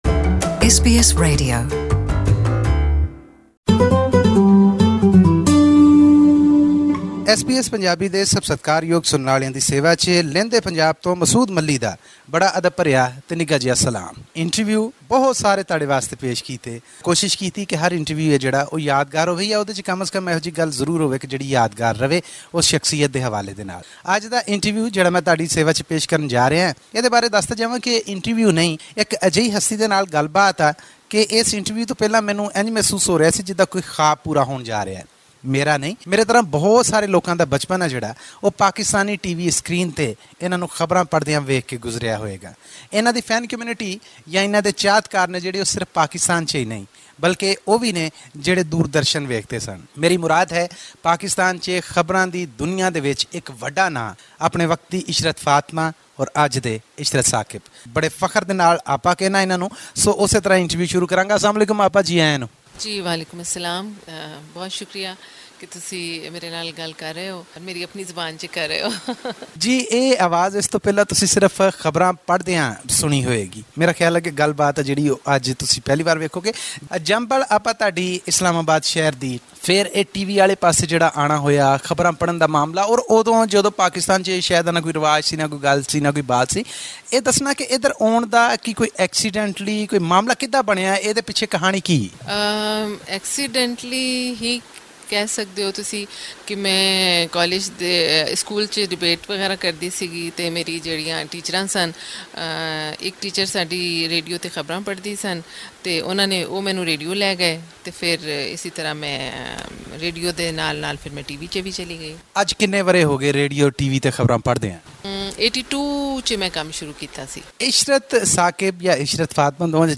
This week, SBS Punjabi chats with one of the pioneers of television news casting in the Indian Subcontinent.